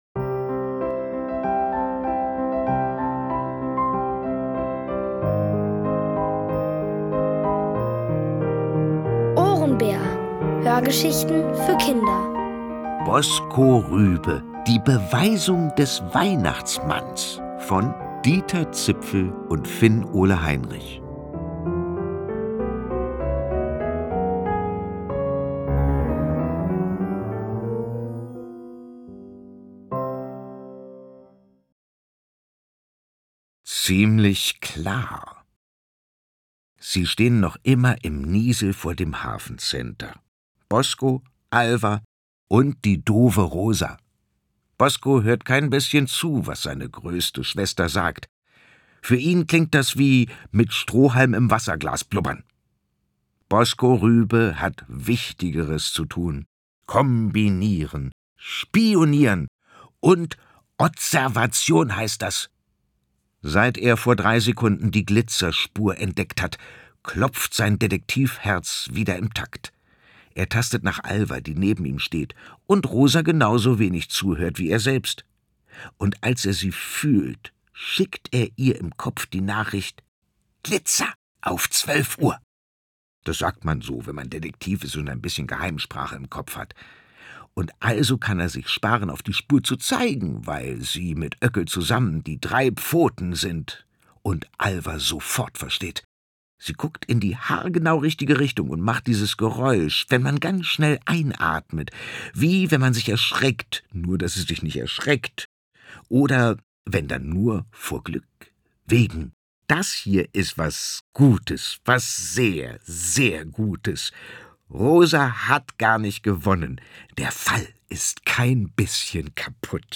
Erzählt von Boris Aljinovic.